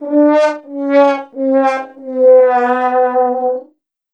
sad-trombone.wav